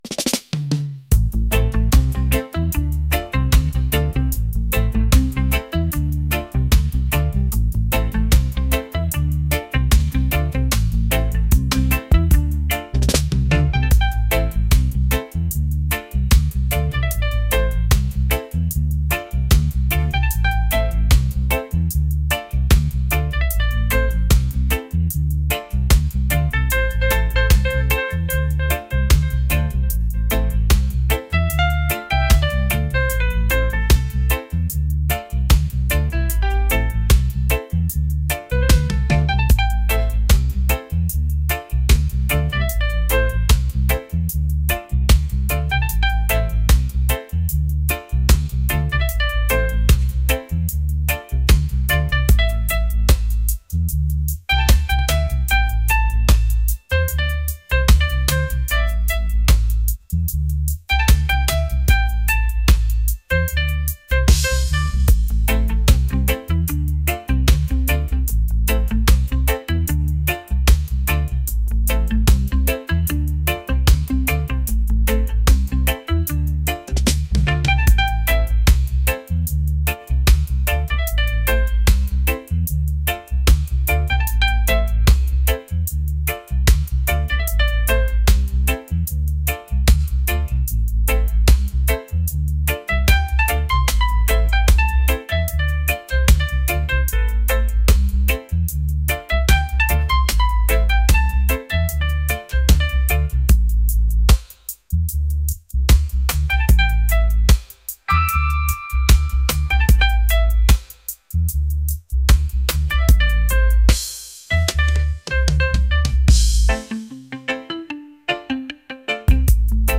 reggae | lively